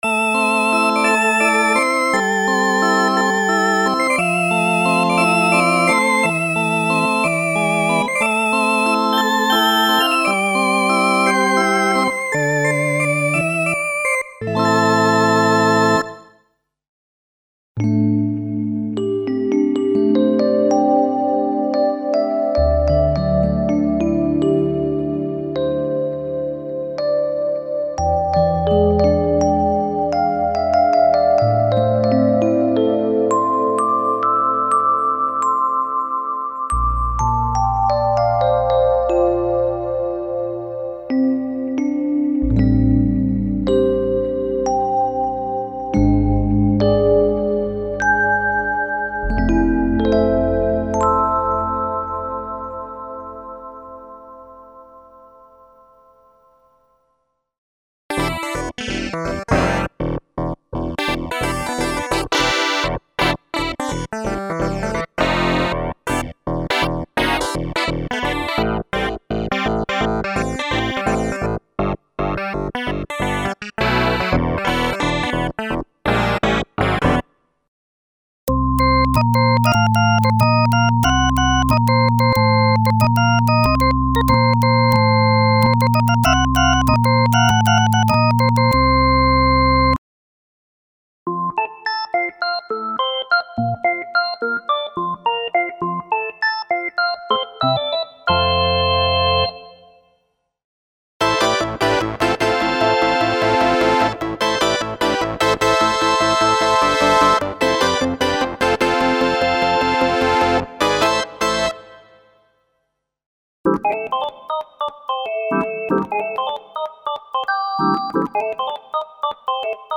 A complete collection of piano and organ sound programs in traditional or less conventional retro feeling, including a complete KB 3 Mode of drawbar organ emulations.
Free Kurzweil K2xxx ROM & FARM sound programs: 270 Info: All original K:Works sound programs use internal Kurzweil K2661 ROM samples exclusively, there are no external samples used.